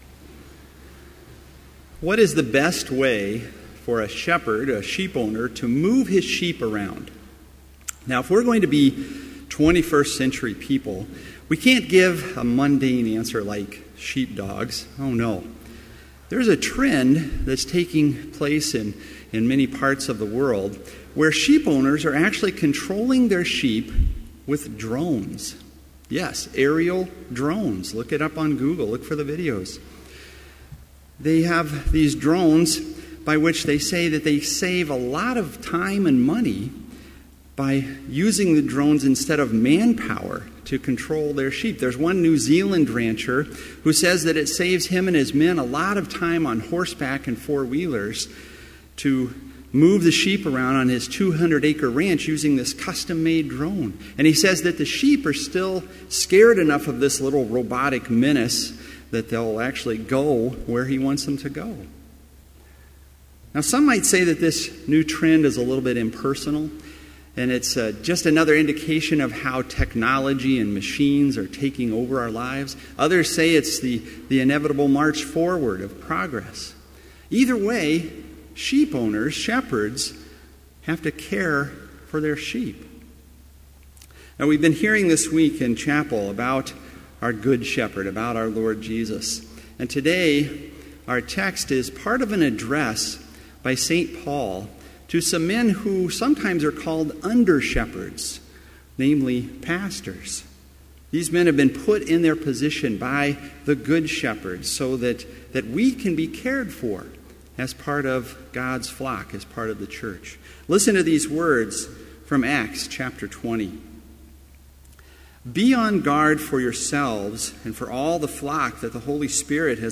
Complete Service
Sermon Only
This Chapel Service was held in Trinity Chapel at Bethany Lutheran College on Thursday, April 23, 2015, at 10 a.m. Page and hymn numbers are from the Evangelical Lutheran Hymnary.